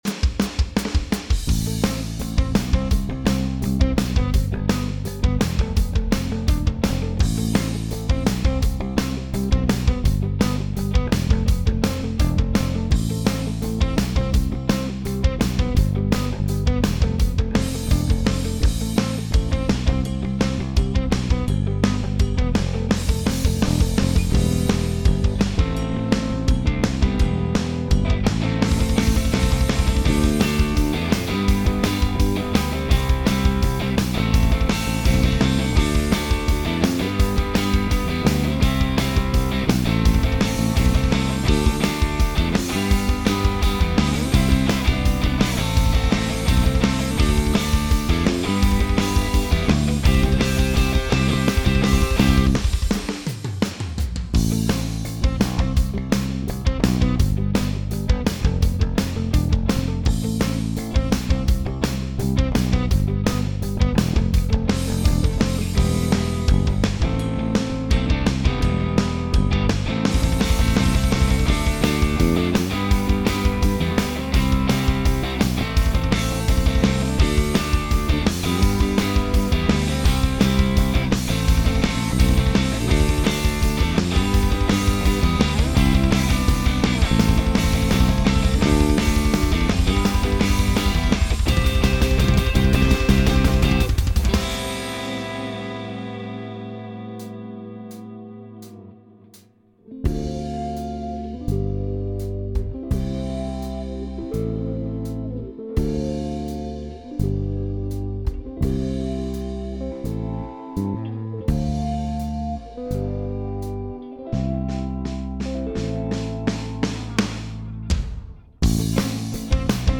Ich habe eben mal grob für die Rock/Pop Version eine Gitarre aufgenommen.
Ich habe nämlich, um diese Gitarre aufzunehmen, den Bass quantisiert.
Anhang anzeigen 156539 (Achja, wenn man das Echo auf der Gitarre klein oder weg macht, bleibt jede Menge Platz zum rummetern.)